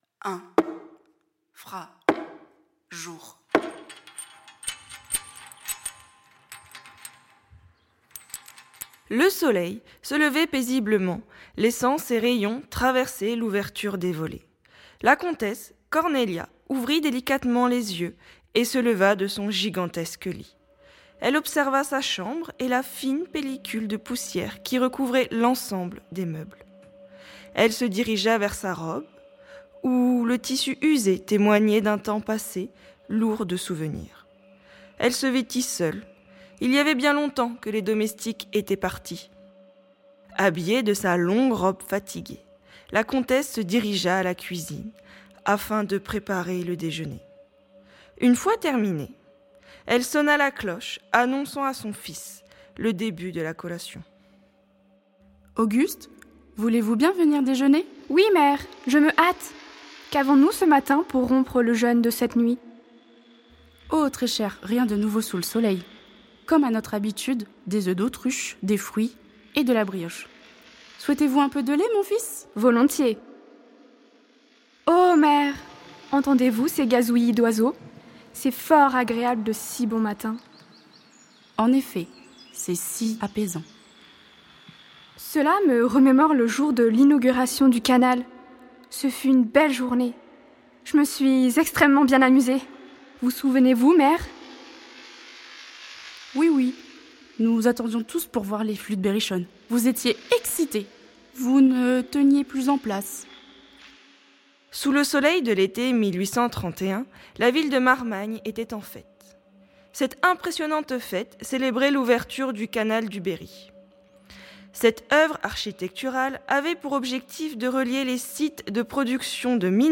Musiques et accompagnements sonores